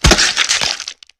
gore5.ogg